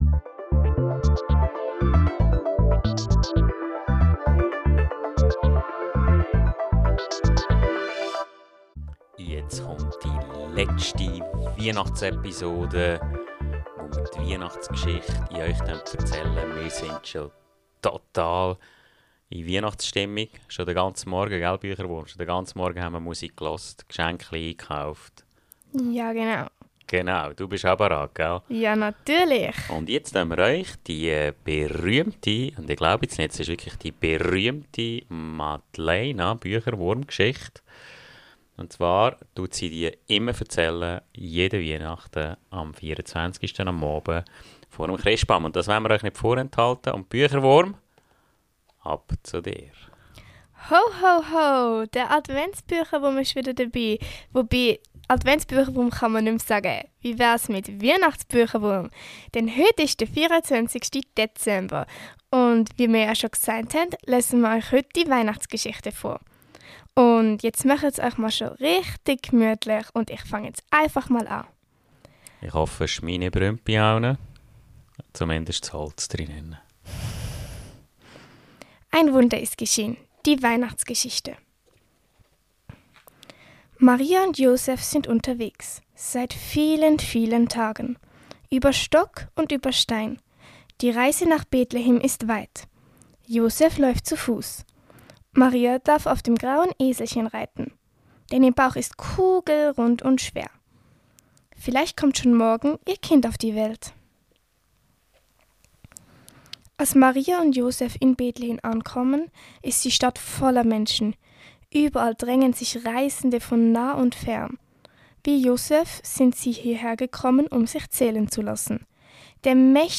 Die persönliche Weichnachtsgeschichte vom Bücherwurm am Mikrofon –